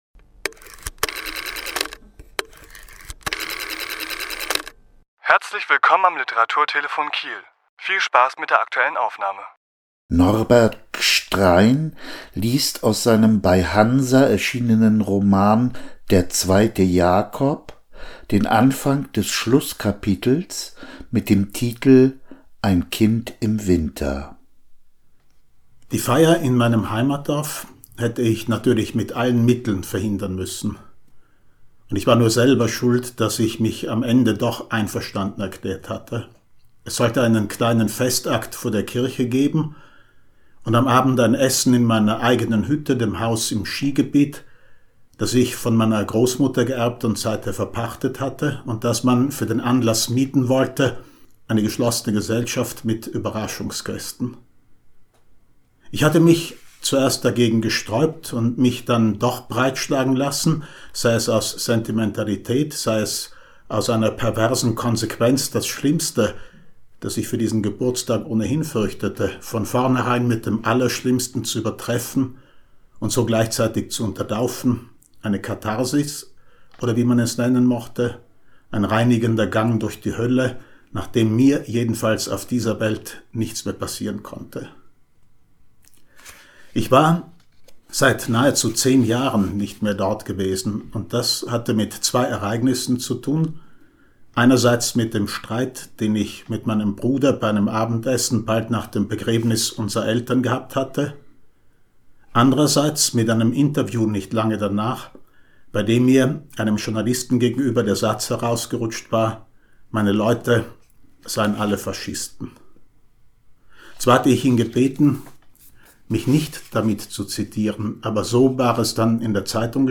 Autor*innen lesen aus ihren Werken
Die Aufnahme entstand im Rahmen einer Lesung am 1.2.2022 im Literaturhaus Schleswig-Holstein.